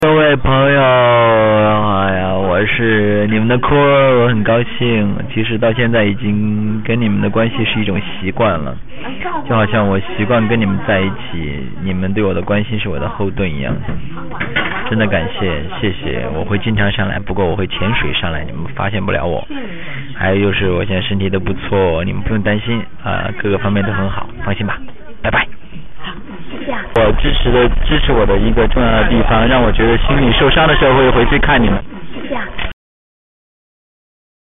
2004年2月8日，陈坤为宣传电影《恋爱中的宝贝》来到武汉，他给支持他的所有朋友们留下了这段很嗲的且很温暖的话语。
坤儿的声音很慵懒,喜欢这种亲切的感觉!
慵懒的声音